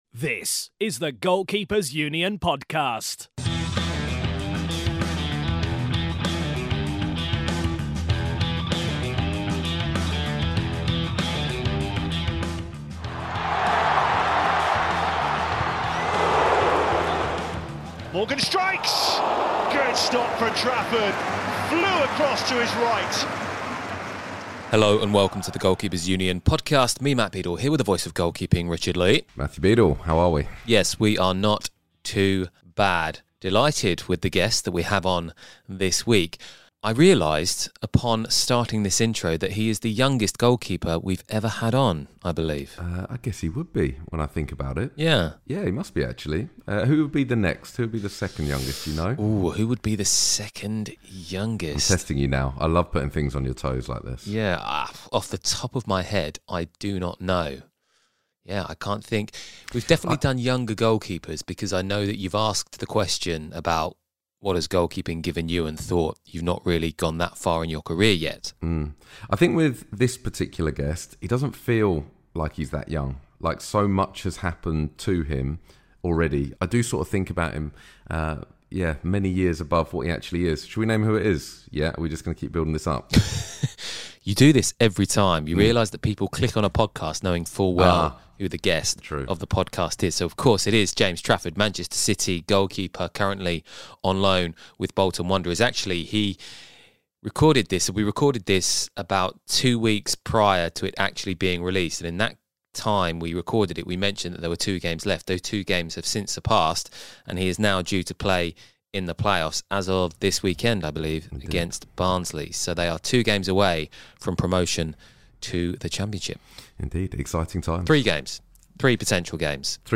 Onto the next feature interview we go